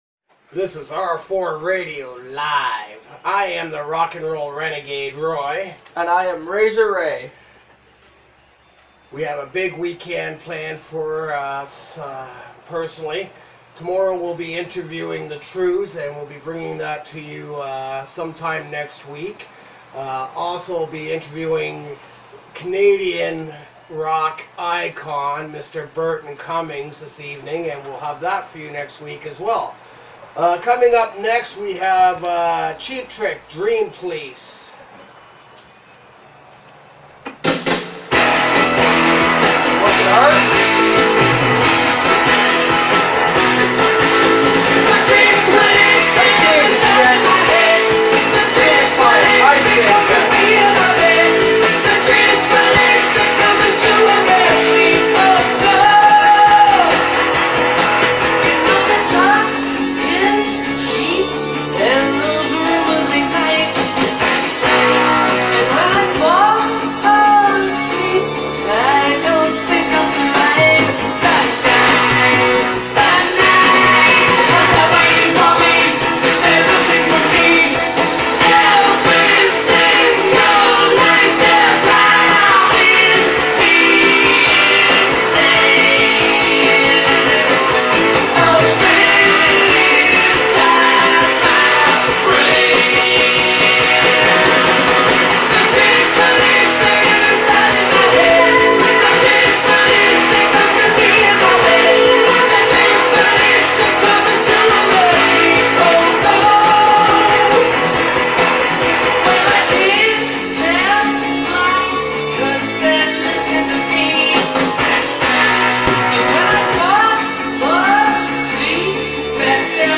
The Best Rock Music, Interviews, Concerts, and Events